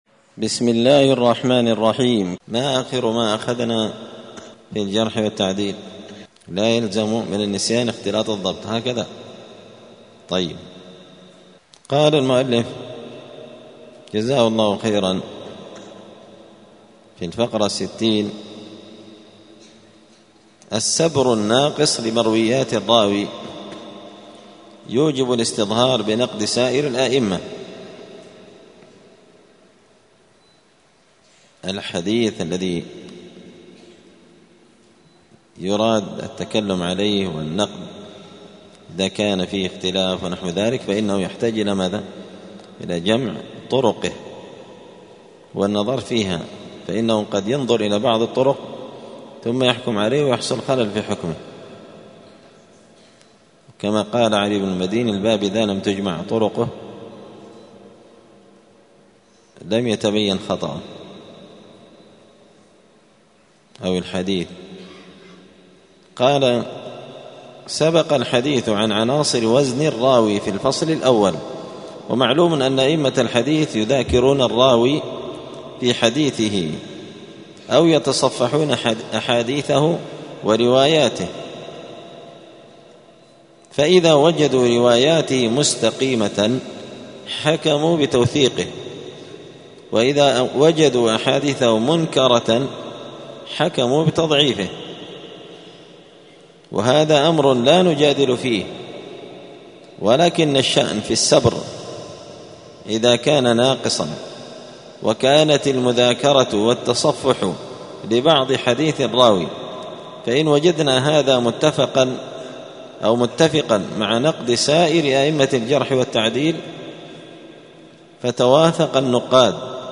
الثلاثاء 28 ربيع الأول 1446 هــــ | الدروس، المحرر في الجرح والتعديل، دروس الحديث وعلومه | شارك بتعليقك | 34 المشاهدات